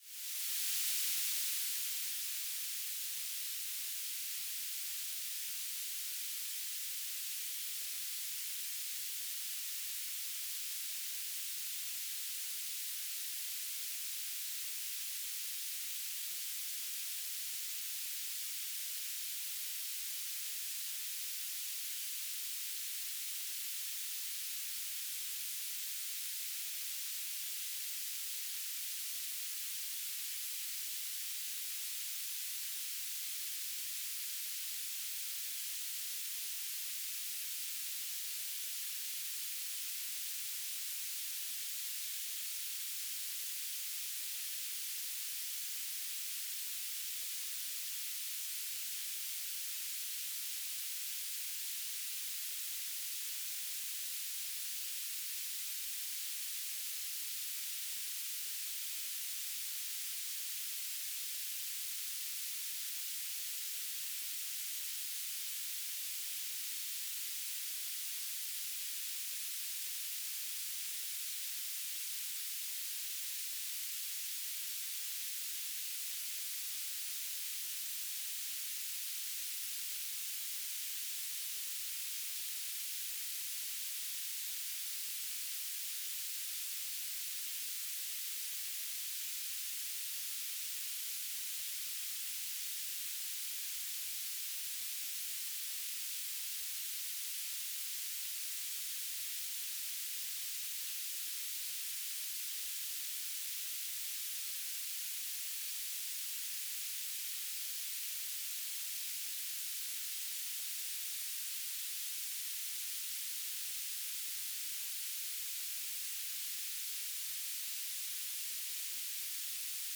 "transmitter_description": "S-band telemetry",
"transmitter_mode": "BPSK",